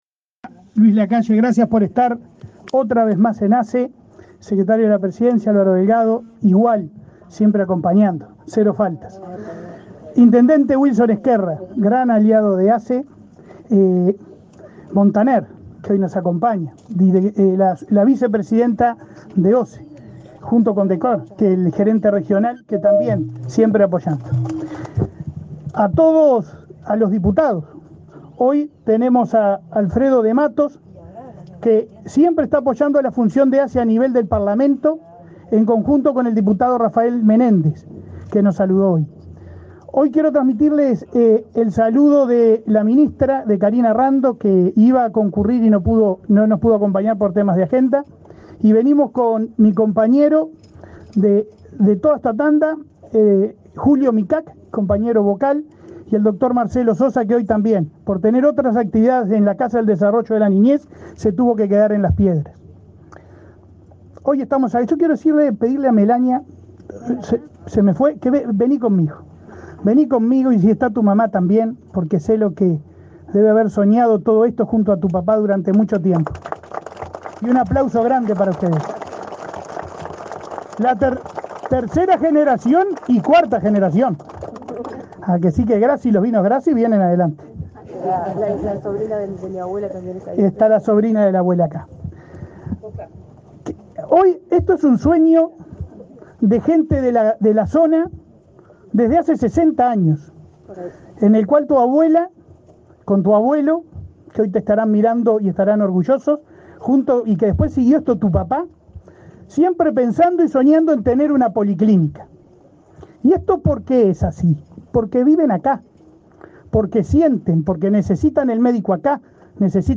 Palabras del presidente de ASSE, Leonardo Cipriani
Palabras del presidente de ASSE, Leonardo Cipriani 19/05/2023 Compartir Facebook X Copiar enlace WhatsApp LinkedIn Con la presencia del presidente de la República, Luis Lacalle Pou, la Administración de los Servicios de Salud del Estado (ASSE), inauguró, este 19 de mayo, la policlínica en la localidad de Quiebra Yugos, en el departamento de Tacuarembó. En el evento el presidente de ASSE, Leonardo Cipriani, realizó declaraciones.